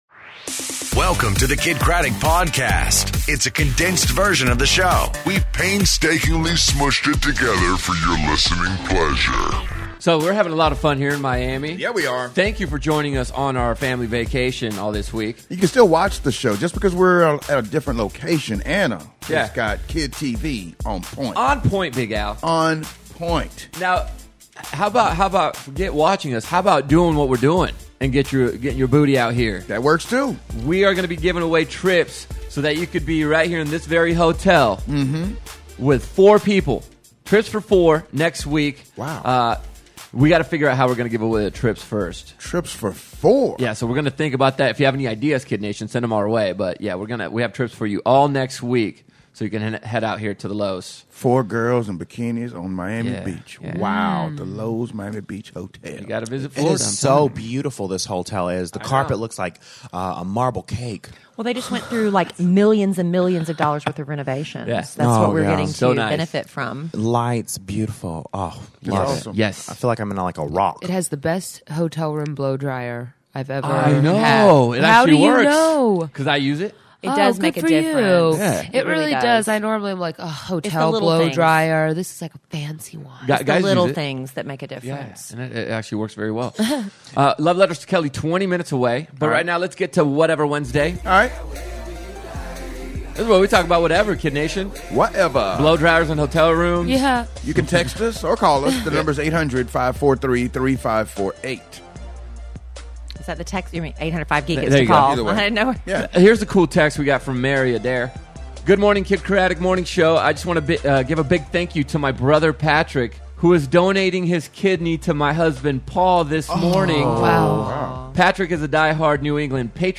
Day 3 In Miami For The Kiddnation Family Vacation!